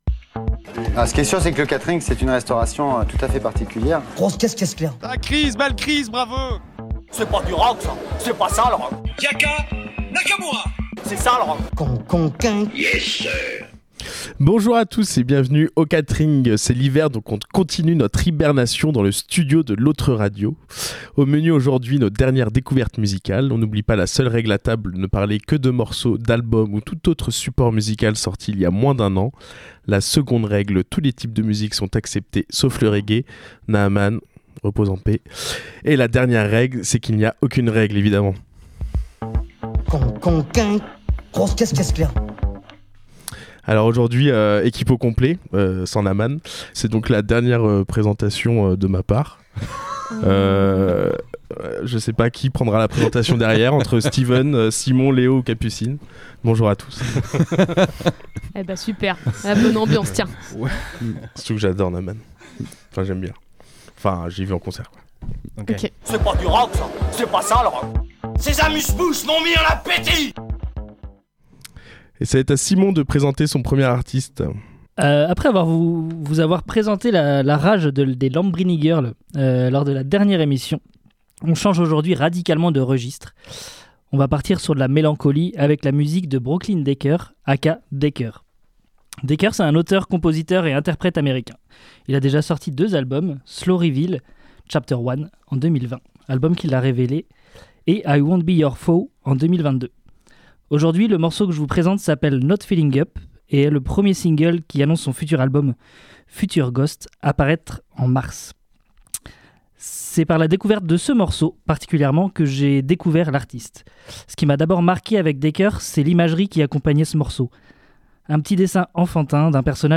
On est bien accueilli dans ce très chouette bar lavallois et, du coup (santé), on partage nos coups de cœur mais aussi nos coups de gueule et nos incompréhensions face aux choix budgétaires de la Région...